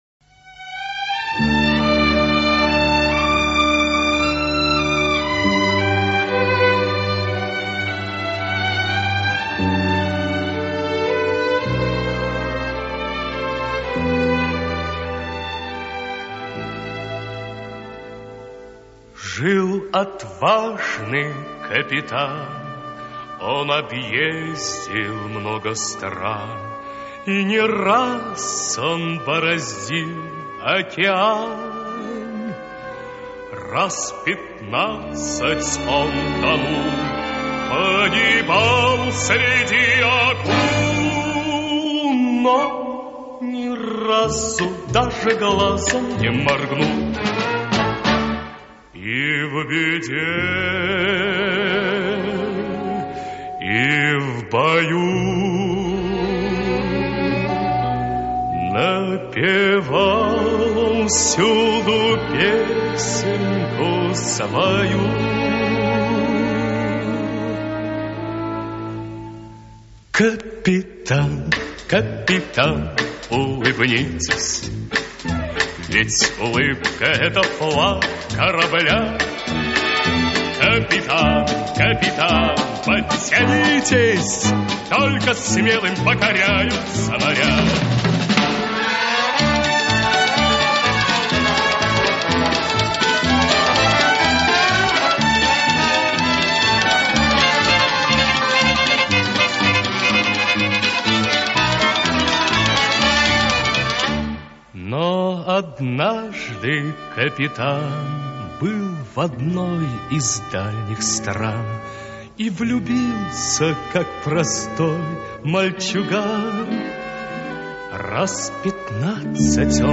это веселая песня